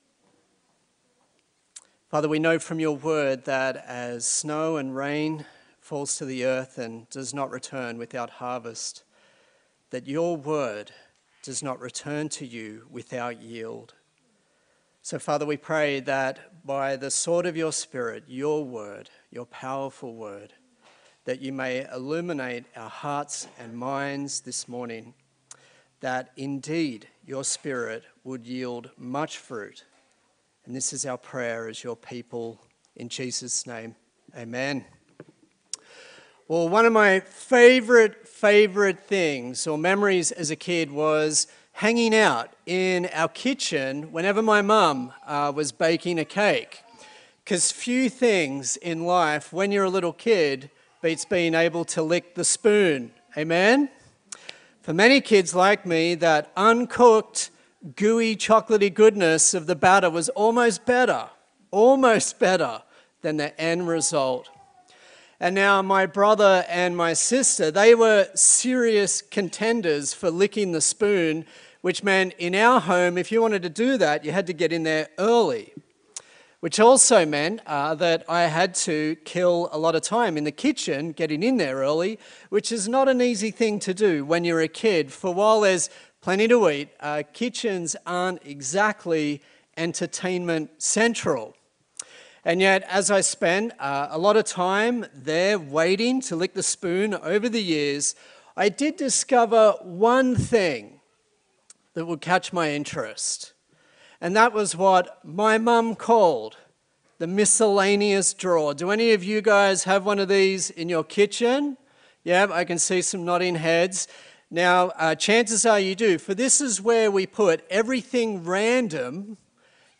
Acts Passage: Acts 18:12-28 Service Type: Sunday Service